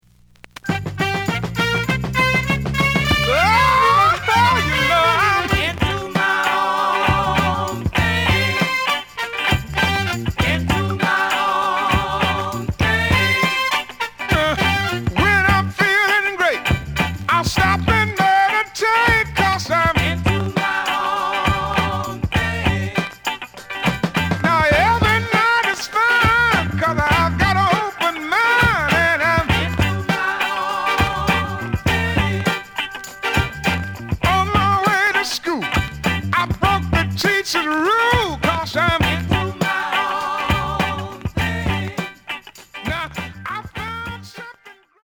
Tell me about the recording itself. The audio sample is recorded from the actual item. ●Format: 7 inch Some noise on A side due to noticeable scratches.)